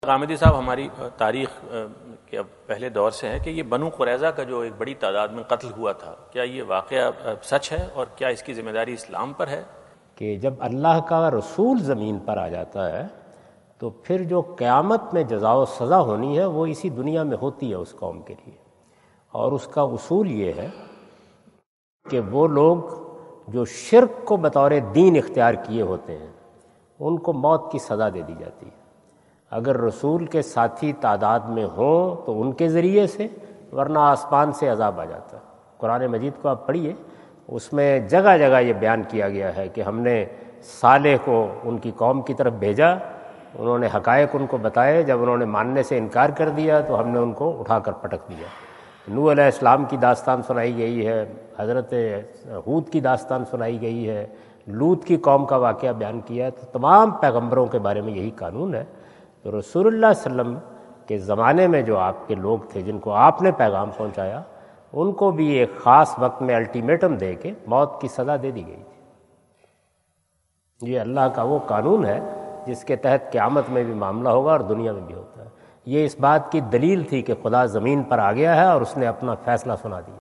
Javed Ahmad Ghamidi answer the question about "Incident of Banu Quraizah and Islam?" During his US visit at Wentz Concert Hall, Chicago on September 23,2017.